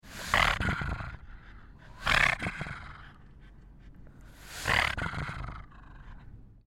Звуки мопса
Звук мопса хрип с дыханием вариант 2